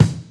• Sharp Kick Sound E Key 191.wav
Royality free kickdrum sound tuned to the E note. Loudest frequency: 465Hz
sharp-kick-sound-e-key-191-NiB.wav